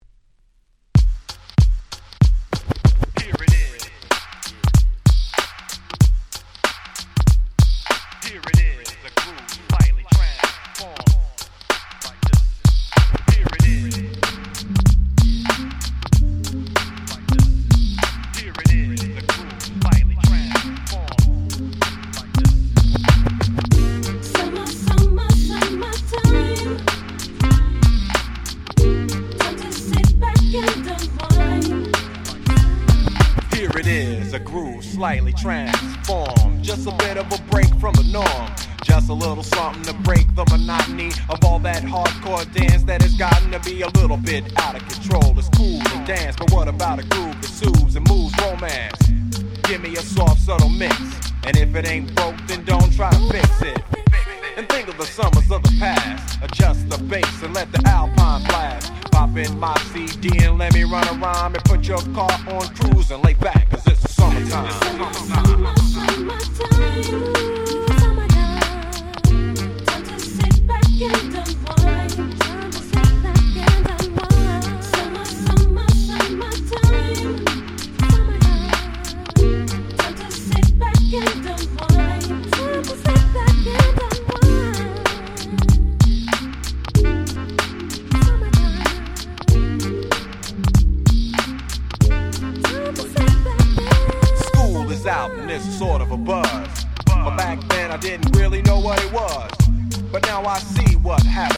98' Smash Hit Hip Hop !!